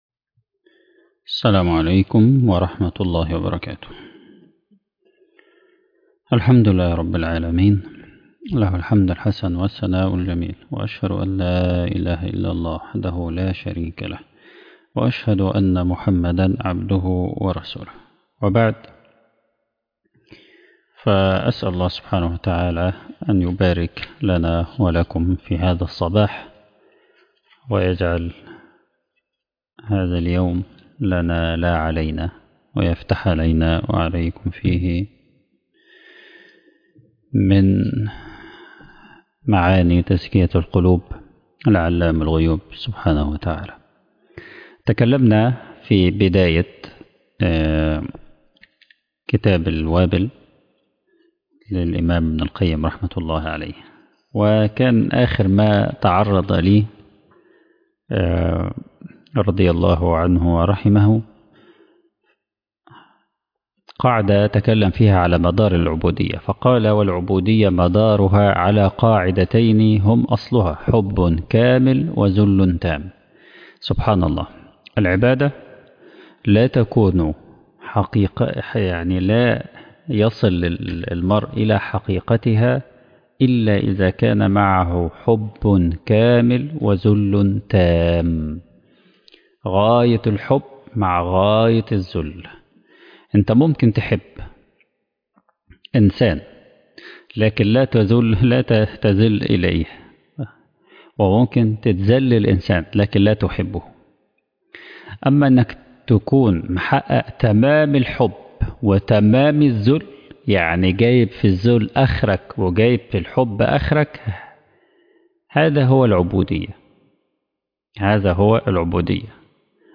التعليق على كتاب الوابل الصيب - الدرس الثانى